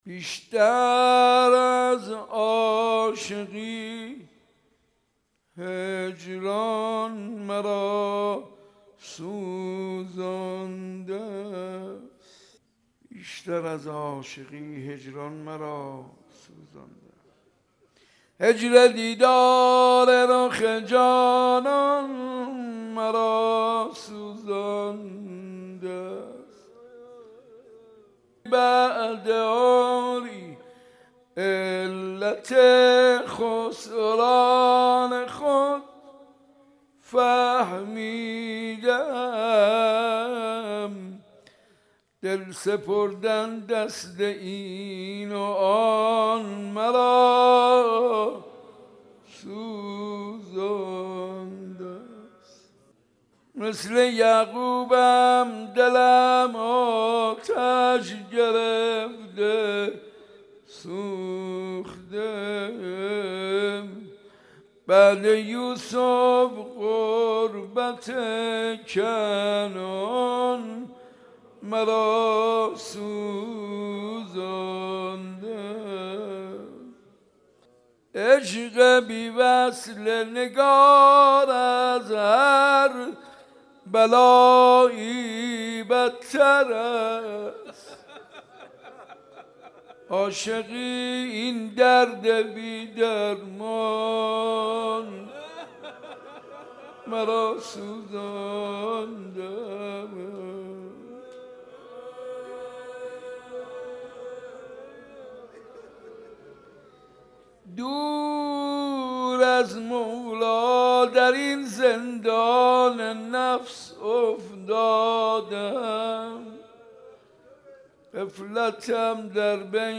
حاج منصور ارضی/حسینیه صنف لباسفروشان/مناجات و روضه حضرت علی اکبر(ع)